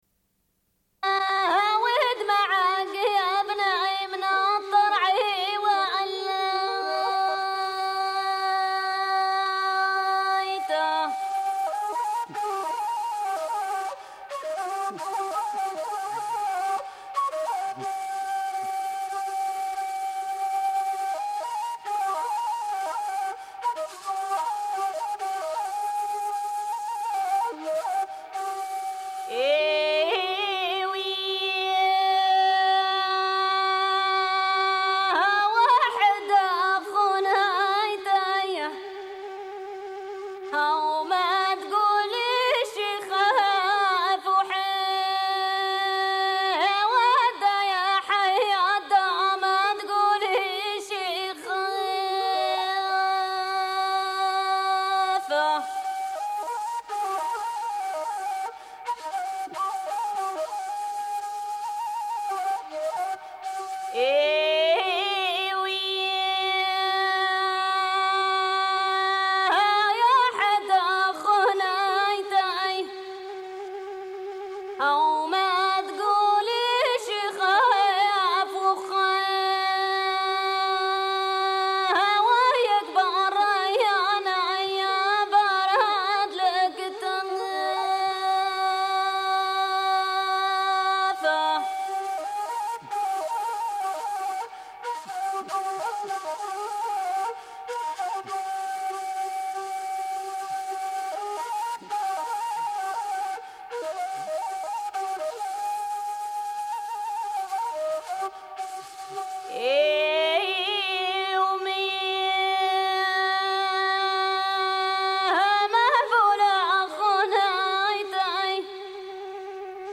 Une cassette audio, face B29:08